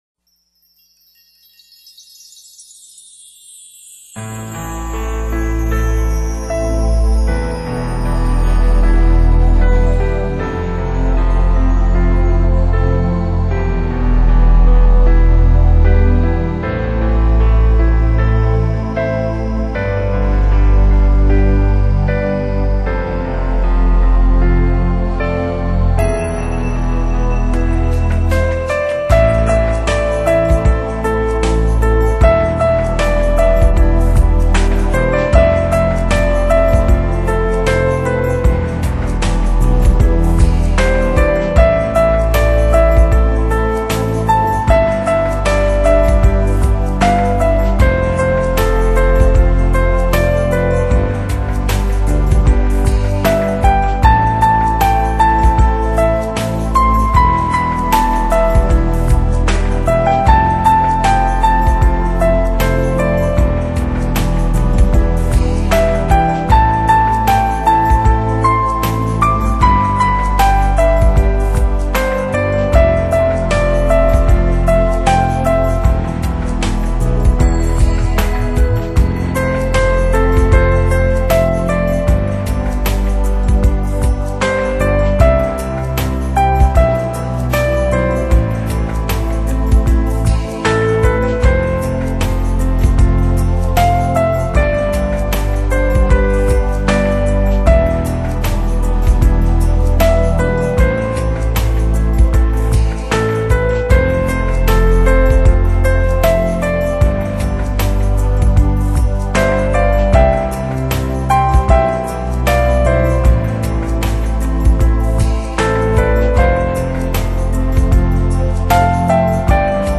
钢琴与吉他的结合